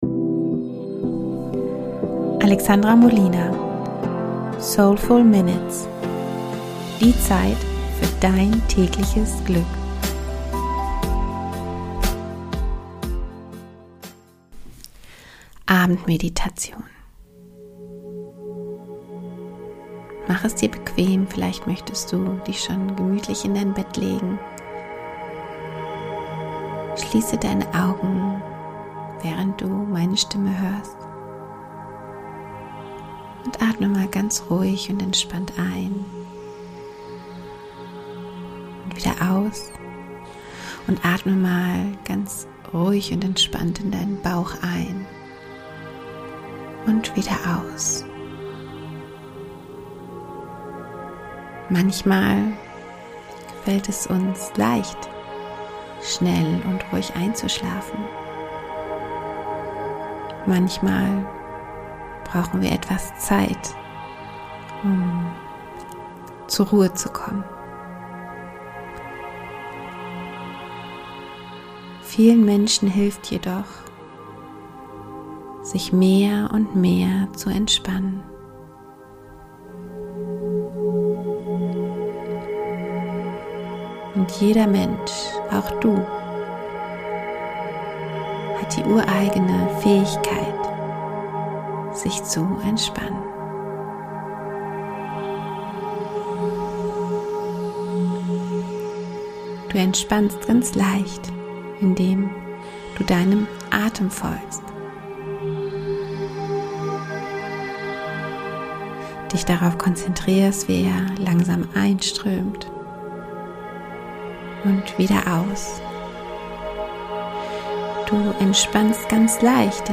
Best of! Schöne Träume - Meditation für eine gute Nacht